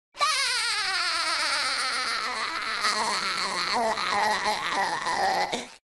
Spongebob Laughing High Pitched Sound Effect Download: Instant Soundboard Button
Sound Effects Soundboard313 views